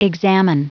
Prononciation du mot examine en anglais (fichier audio)
examine.wav